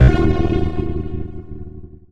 retro_fail_sound_04.wav